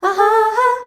AHAAA   G.wav